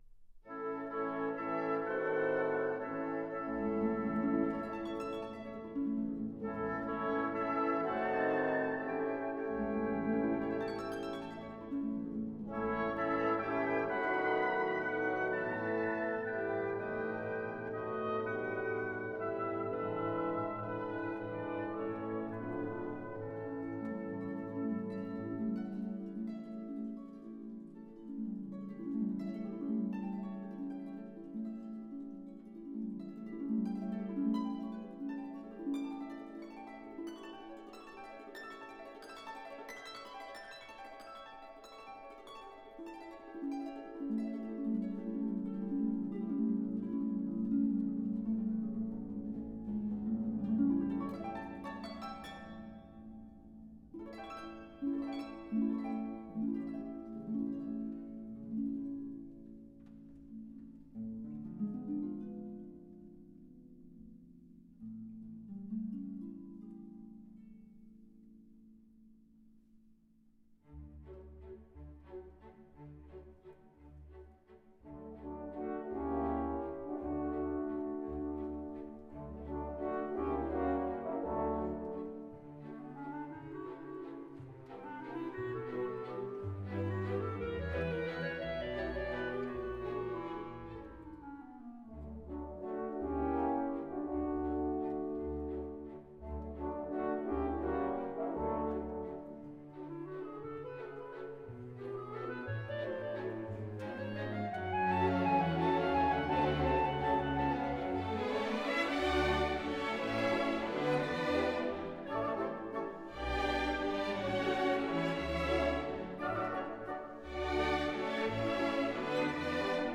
73-the-nutcracker-op.-71a_-xiv.-waltz-of-the-flowers_-tempo-di-valse.m4a